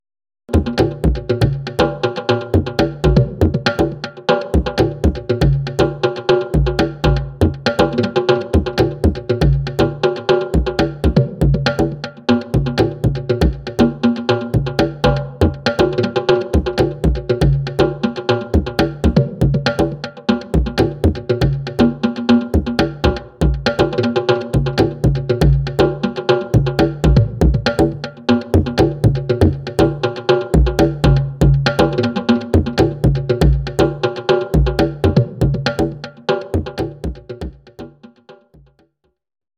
Drum Modelling Examples
twin drums ribattuto
twin_drums ribattuto.mp3